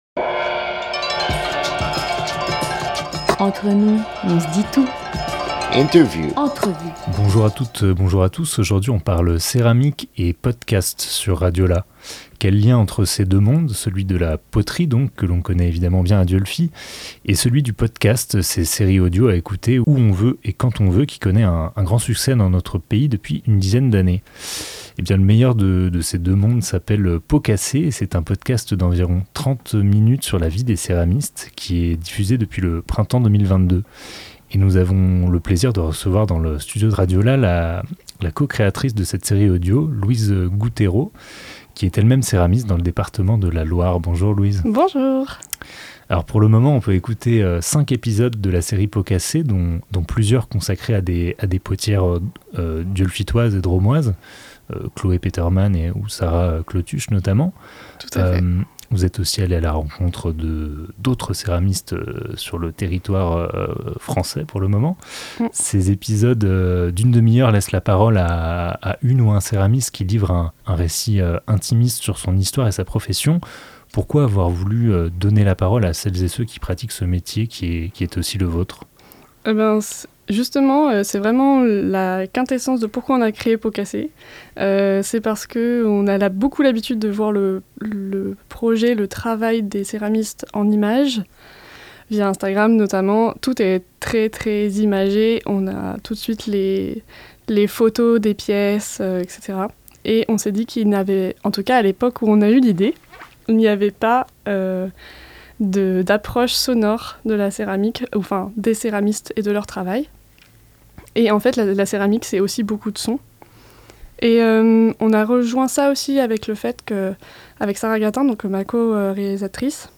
11 avril 2023 11:58 | Interview